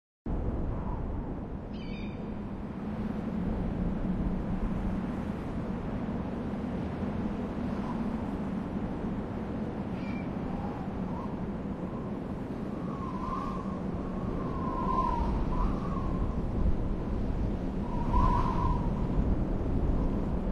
دانلود آهنگ صحرا 1 از افکت صوتی طبیعت و محیط
جلوه های صوتی
دانلود صدای صحرا 1 از ساعد نیوز با لینک مستقیم و کیفیت بالا